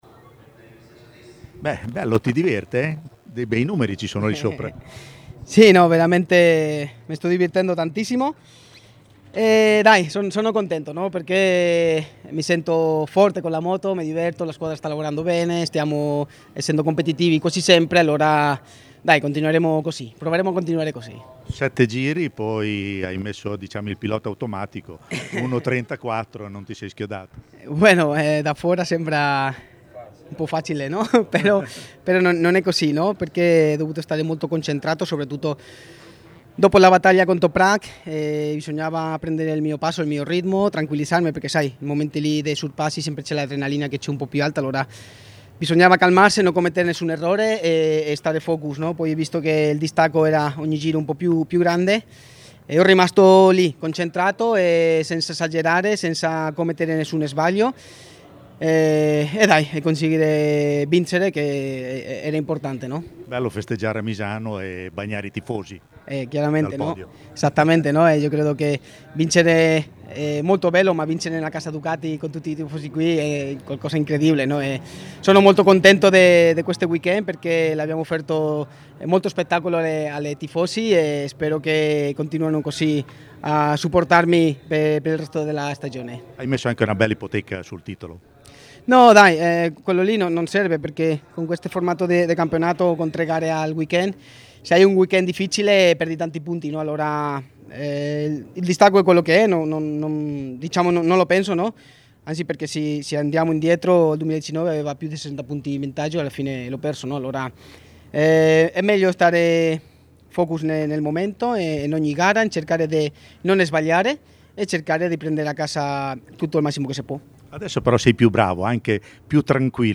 Ascolta l’intervista a Alvaro Bautista, che concede il bis al “Simoncelli” di Misano e porta a 34 punti il vantaggio nella classifica del Mondiale Superbike su Jonathan Rea: